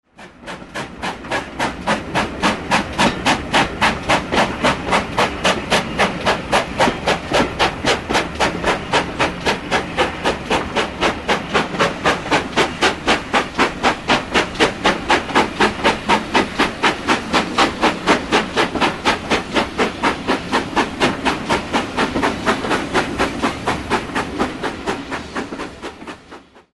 This fourth volume of tracks are all on-train recordings.
This recording starts as, after passing through Huddersfield at reduced speed, the loco emerges from Paddock Tunnel.
Eventually we reach level track beyond Marsden and enter Standedge Tunnel where this recording ends.